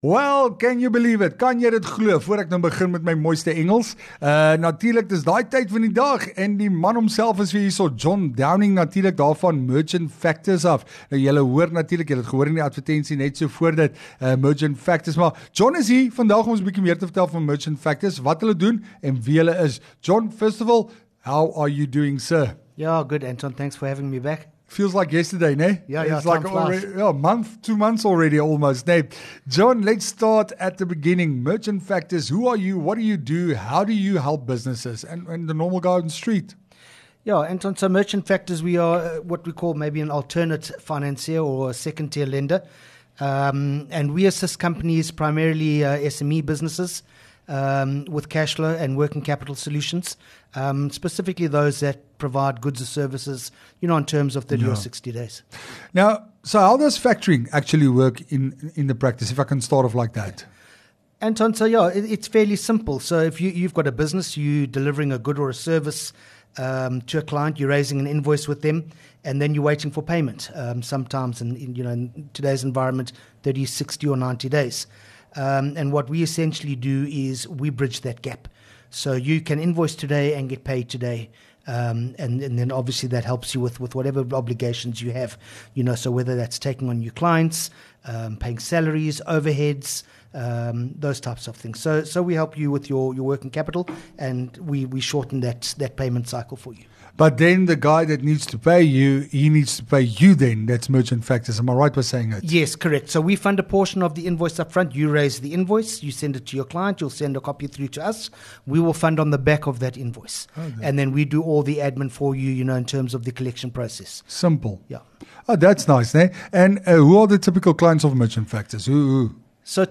LEKKER FM | Onderhoude 14 May Merchant Factors